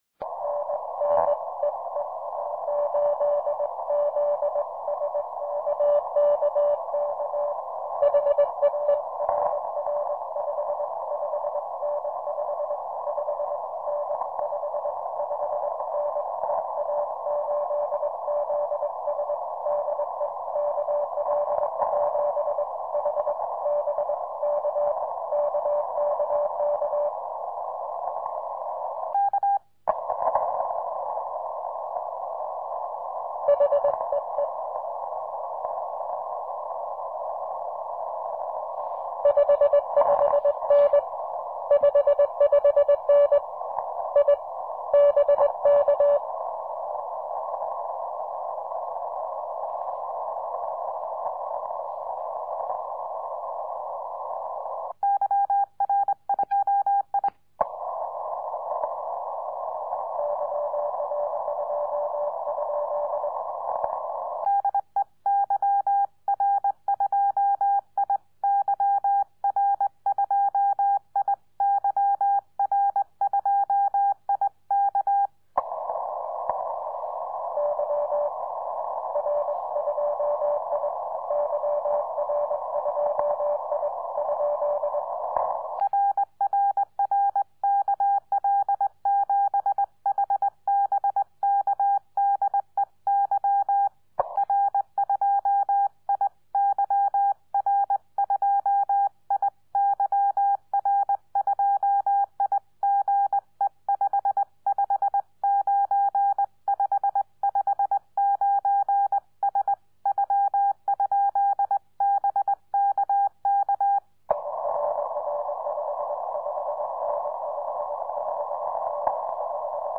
Zgomotul benzii era nefiresc de mic.